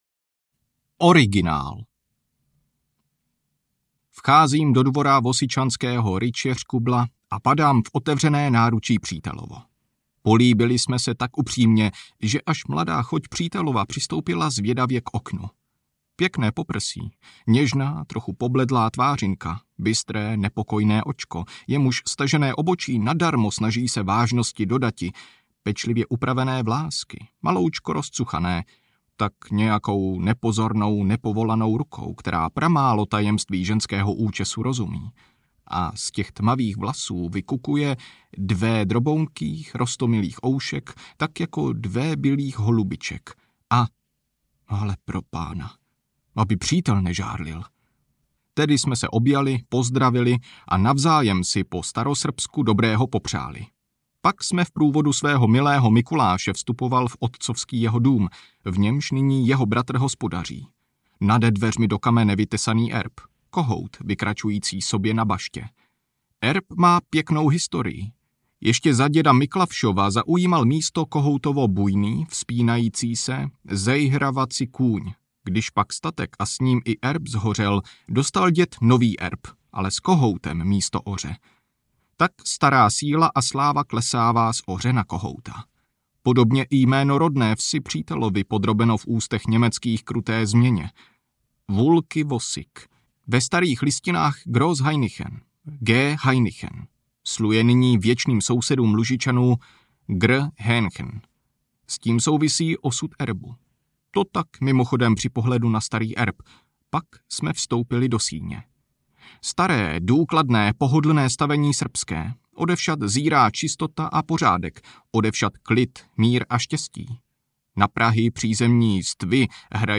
Lužické obrázky audiokniha
Ukázka z knihy